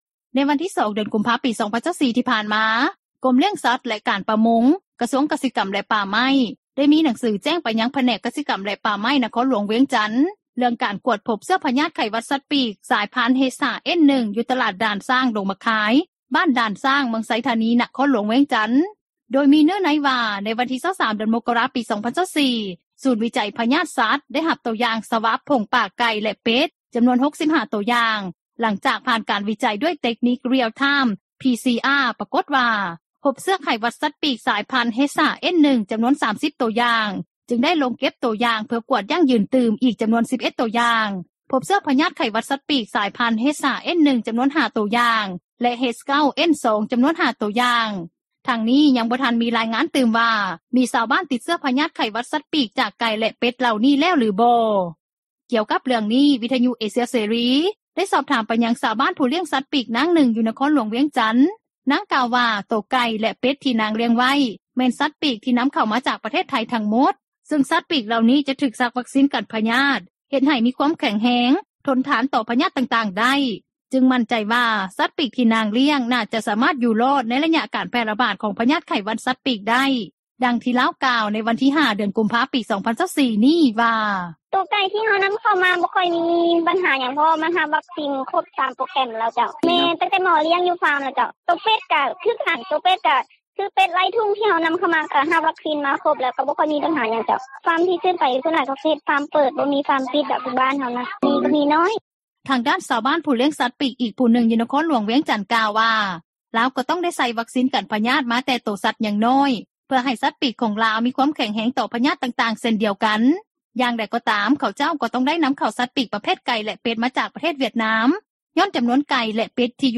ກ່ຽວກັບເຣື່ອງນີ້, ວິທຍຸເອເຊັຽເສຣີ ໄດ້ສອບຖາມໄປຍັງ ຊາວບ້ານຜູ້ລ້ຽງສັດປີກ ນາງນຶ່ງ ຢູ່ນະຄອນຫຼວງວຽງຈັນ. ນາງກ່າວວ່າ ໂຕໄກ່ ແລະເປັດ ທີ່ນາງລ້ຽງໄວ້ ແມ່ນສັດປີກ ທີ່ນຳເຂົ້າມາຈາກປະເທດໄທຍທັງໝົດ ຊຶ່ງສັດປີກເຫຼົ່ານີ້ ຈະຖືກສັກວັກຊີນກັນພະຍາດ ເຮັດໃຫ້ ມີຄວາມແຂງແຮງ ທົນທານຕໍ່ພະຍາດຕ່າງໆໄດ້ ຈຶ່ງໝັ້ນໃຈວ່າ ສັດປີກທີ່ນາງລ້ຽງ ໜ້າຈະສາມາດຢູ່ລອດ ໃນໄລຍະການແຜ່ຣະບາດ ຂອງພະຍາດໄຂ້ຫວັດສັດປີກໄດ້.